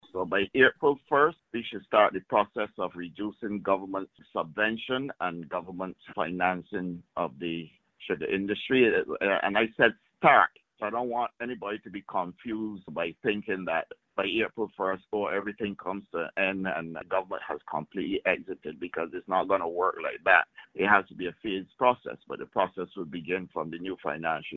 Minister of Agriculture and Food Security, Indar Weir.